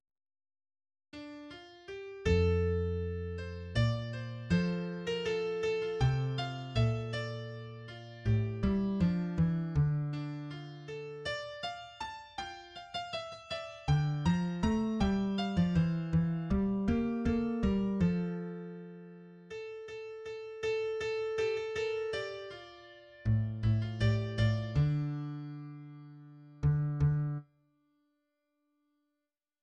\key f \major [( f,2 bes,4 f2 c4 bes,2 )] a,8 g f e d2 R2. r8 d8 f a g8. f16 e8 e g bes bes g f2 r4 r2. r4 a,8 a, a, a, d2~ d8 d d r8 } >> \new Lyrics \lyricsto "three" {\set fontSize = #-2 oo dor -- tn nit mayn shats Vu tre- -- rn kri- -- tsn, Dor- -- tn iz mayn ru- -- e plats Dor- -- tn iz mayn ru- -- e plats } >> \midi{}